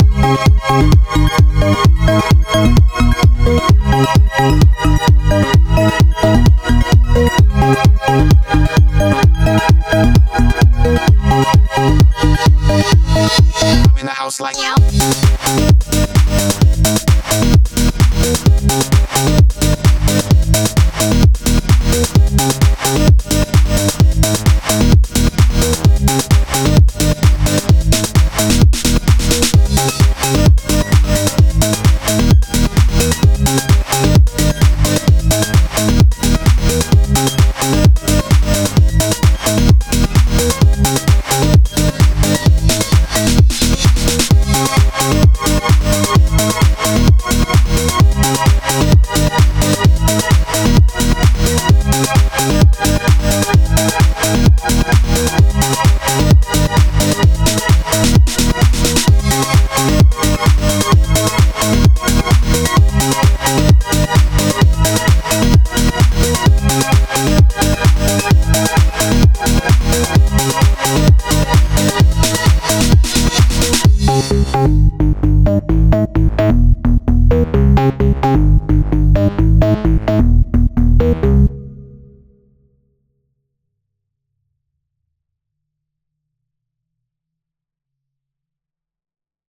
130 BPM